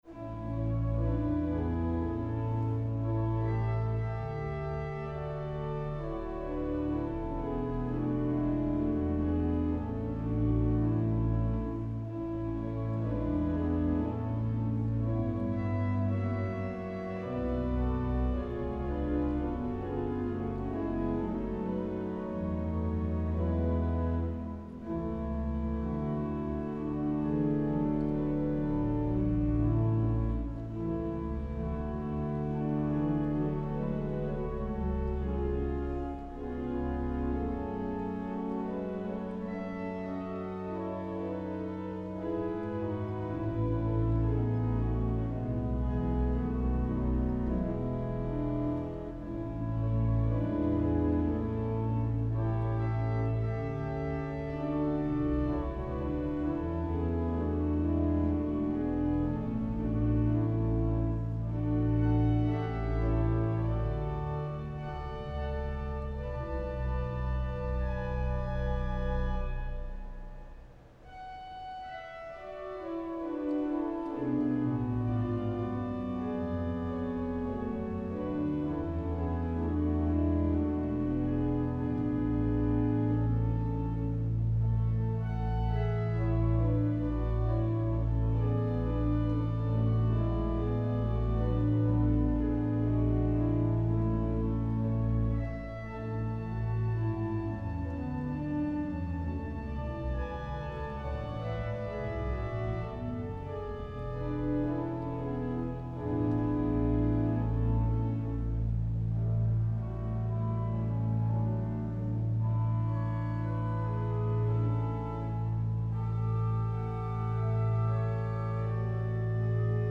Nagrania archiwalne wykonane przed demontażem organów w 2016 roku. Ze względu na stan instrumentu nie było możliwe wykonanie całości utworów.